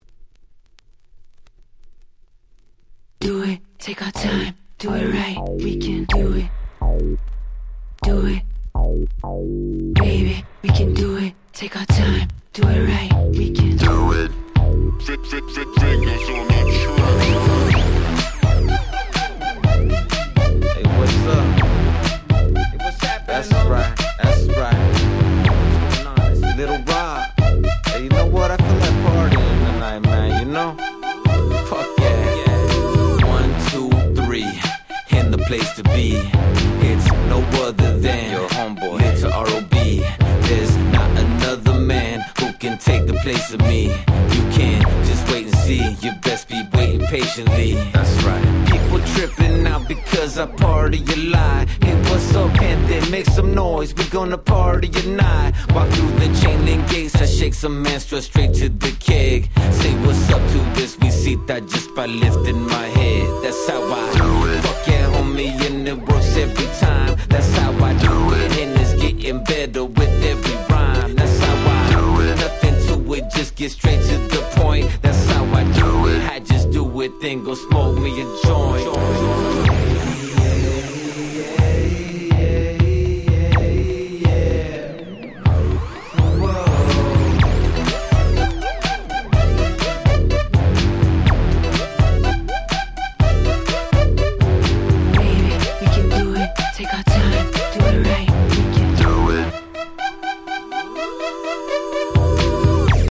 07' Very Nice Chicano Rap !!
West Coast Hip Hop